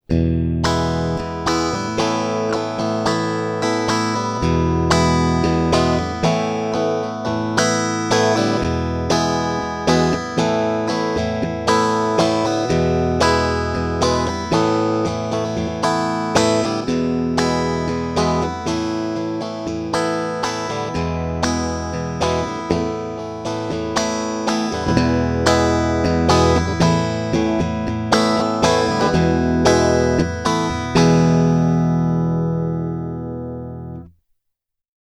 If I recall correctly I put five pedals in channel A of the LS-2 and ten pedals in channel B (or maybe four and eight - it's ten years ago).
Bypass test
(LS-2 in bypass | LS-2, channel A | LS-2, channel B | LS-2 in bypass)
Notice how the volume comes back at 0:24 when I switch back from channel B with lots of bypassed pedals to the LS-2's bypass with no other pedal before or after it.